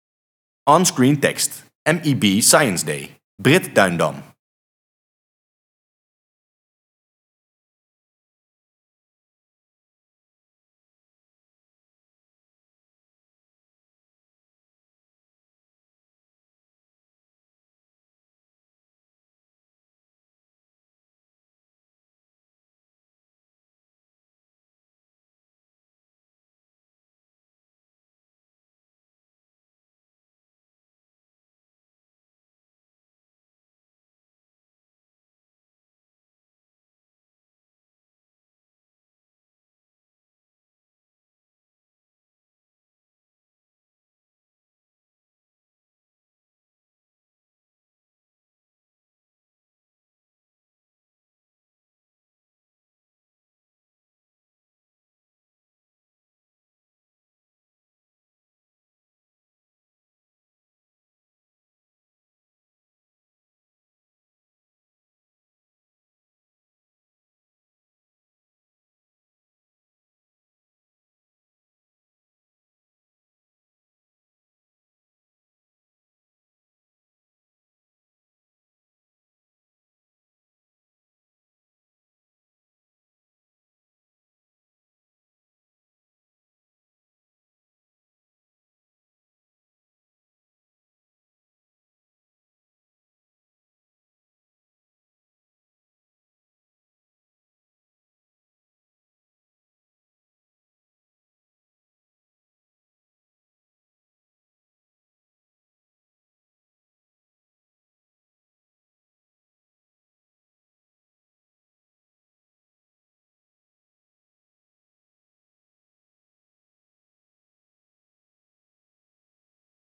*Ambient music plays*